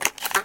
shotgunReload2.ogg